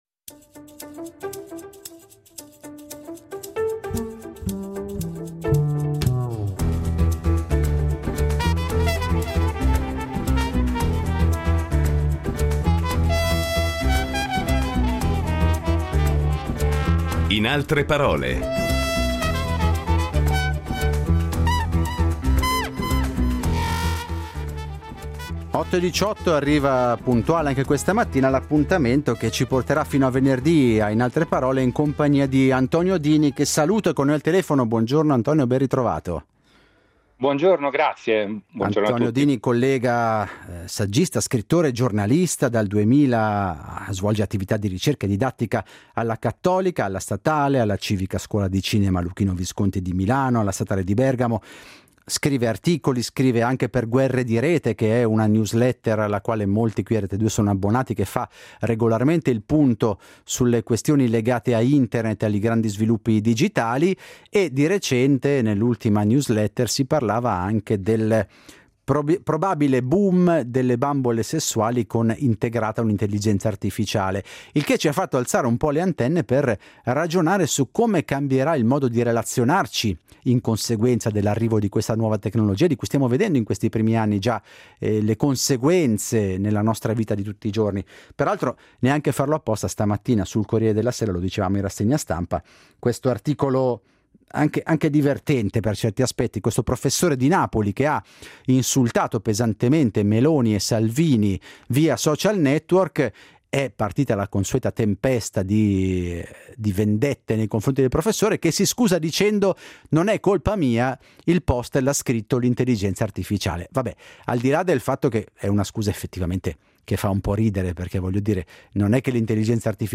Incontro con l’autore, saggista e scrittore